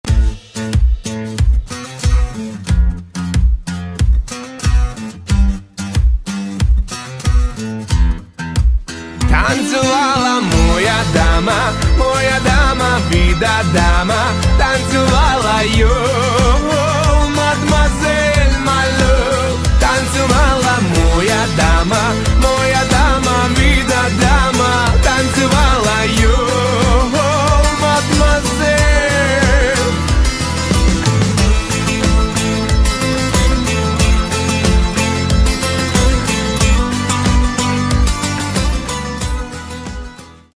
танцевальные
рок